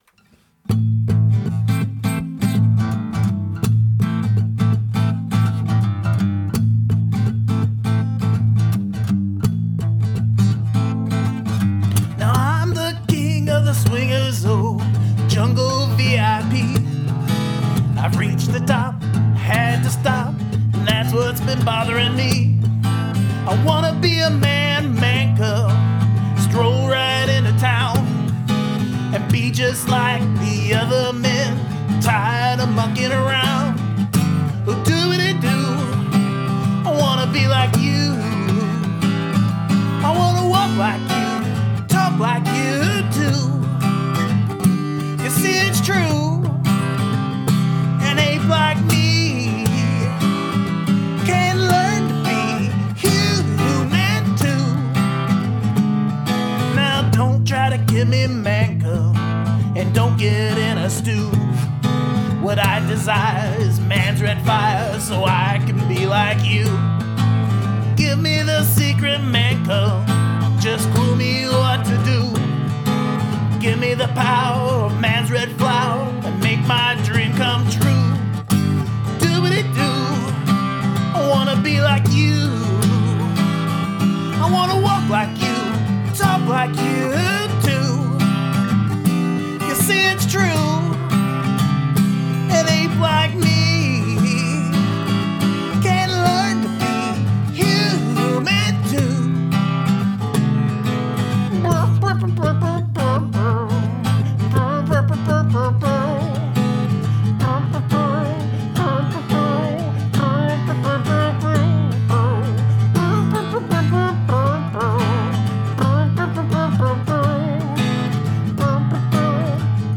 Please pardon the out of tune mouth trumpet.
Top class recording as well!
you turn this into a kind of bluesy boogie version